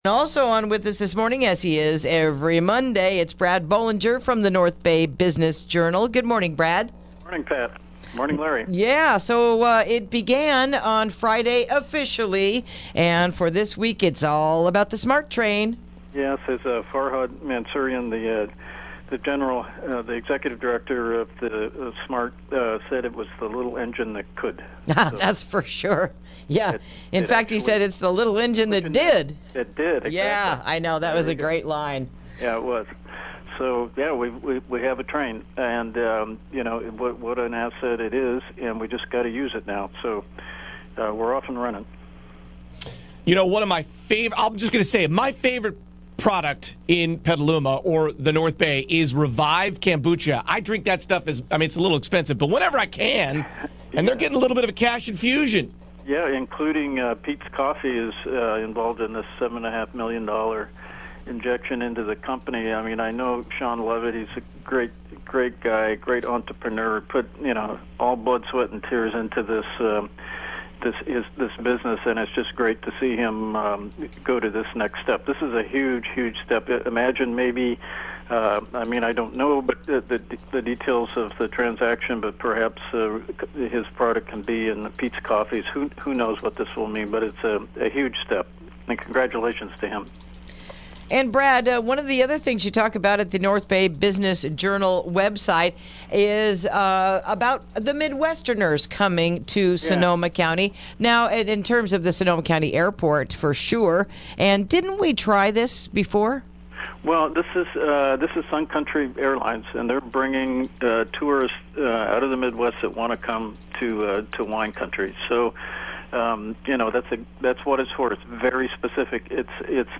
Interview: Takin’ Care of Business